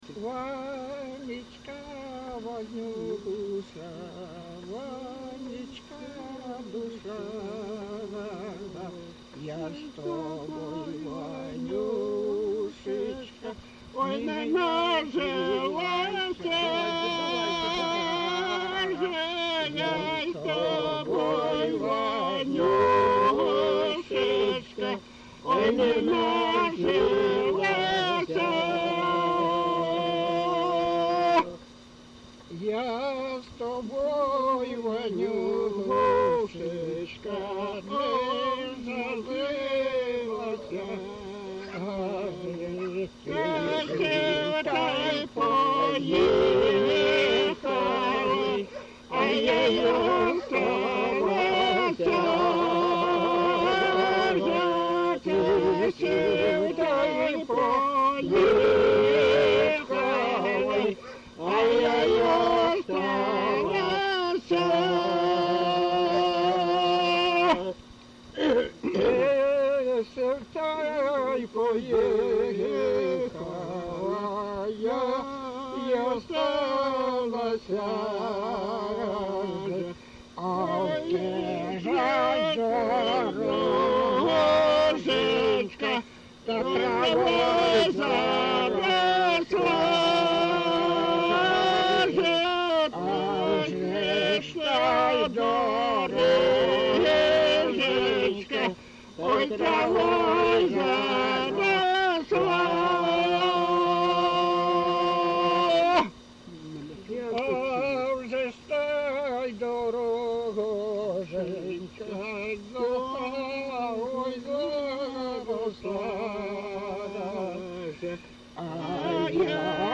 ЖанрПісні з особистого та родинного життя